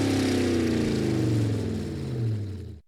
slowingdown.ogg